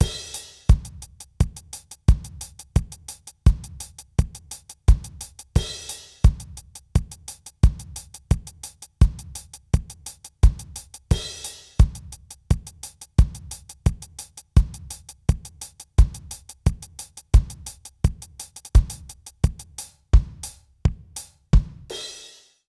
British REGGAE Loop 088BPM.wav